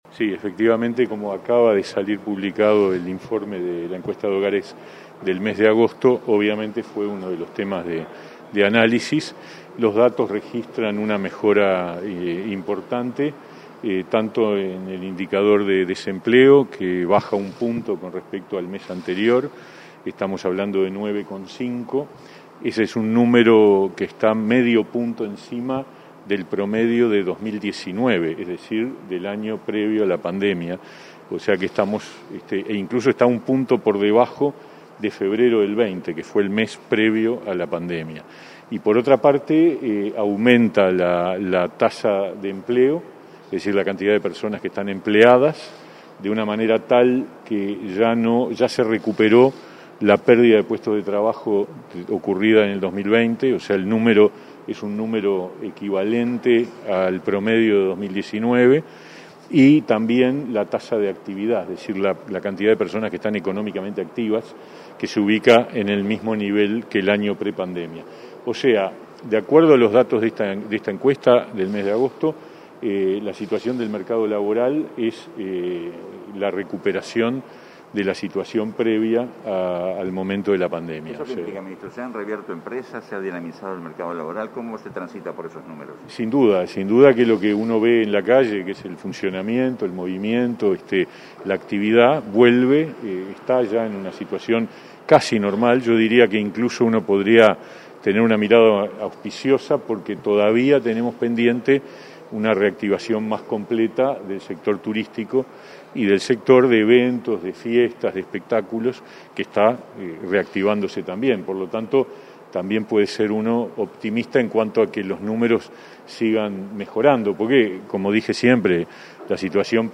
Declaraciones del ministro de Trabajo y Seguridad Social, Pablo Mieres, a la prensa
Tras participar en el acuerdo ministerial con el presidente Luis Lacalle Pou, este 7 de octubre, el ministro Mieres efectuó declaraciones a la prensa.